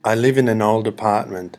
iliveinFAST.mp3